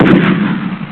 converted_BOMB.wav